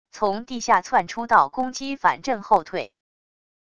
从地下窜出到攻击反震后退wav音频